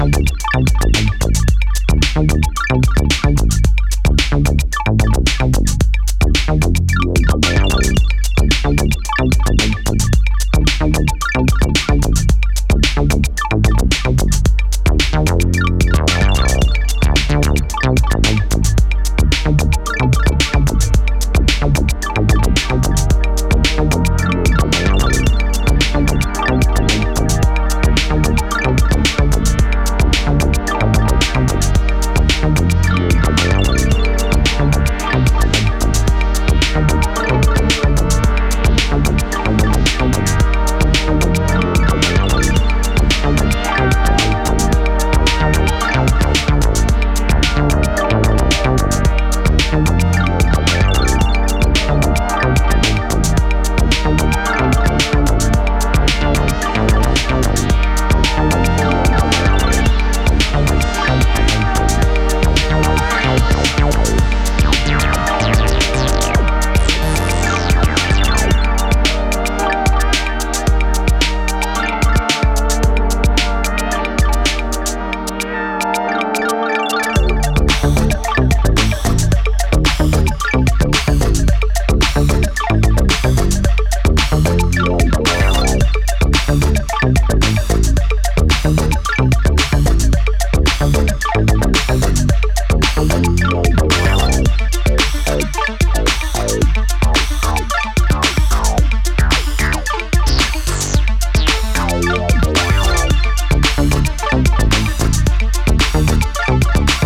captivating and melodic electro EP